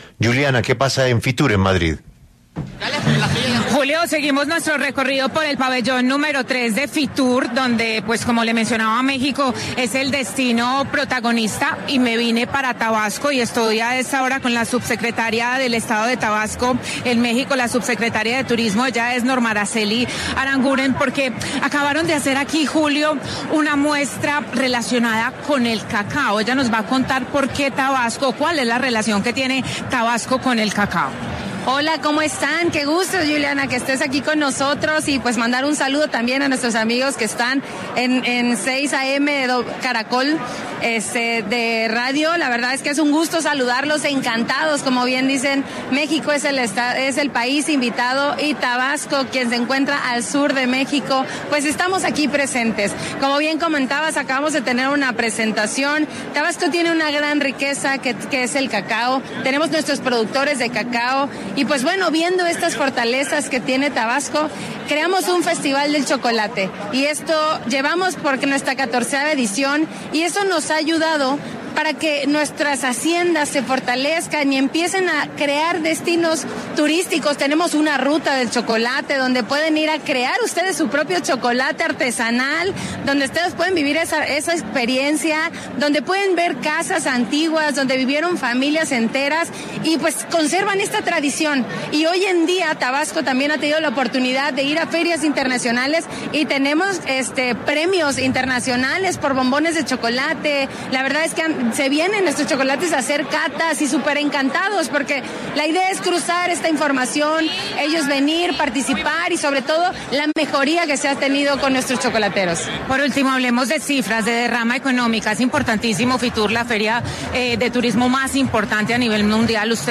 Norma Araceli Aranguren, subsecretaria de Turismo de Tabasco, estado de México, habló con 6AM W desde Fitur, un evento de turismo de nivel mundial que se realiza cada año en Madrid, España.